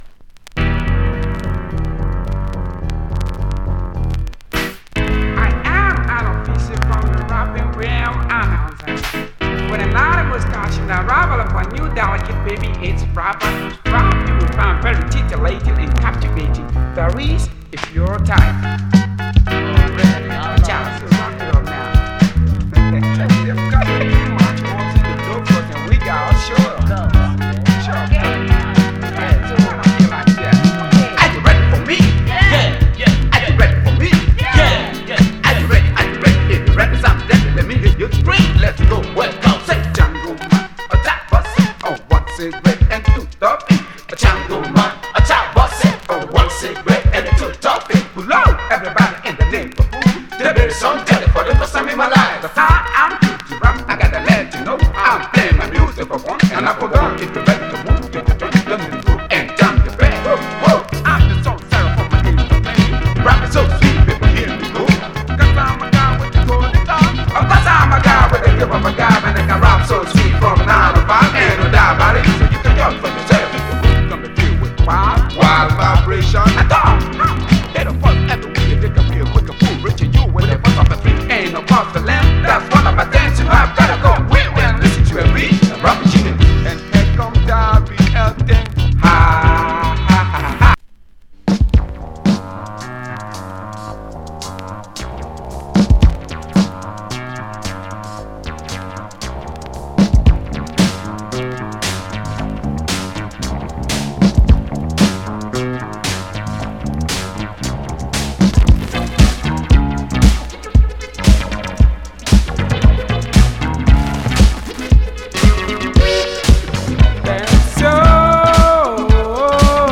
Genre: Hip Hop, Funk / Soul Style: Boogie, Funk